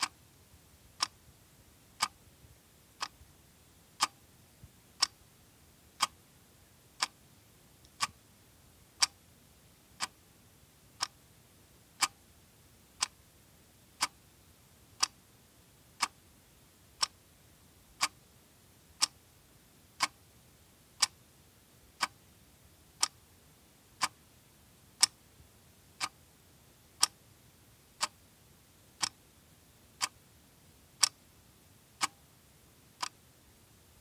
古董时钟滴答滴答
描述：真的很老的时钟大声地走动。 记录于佳能Legria。
标签： 嘀嗒 滴答滴答 老旧 时钟 时间 滴答 警报 古董 古老的声音
声道立体声